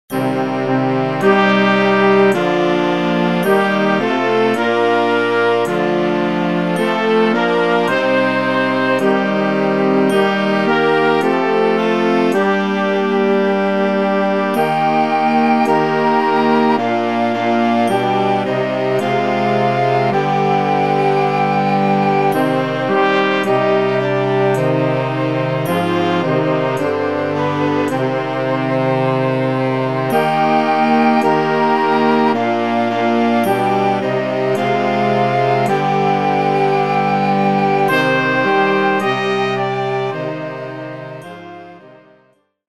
pasyjna
wielkopostna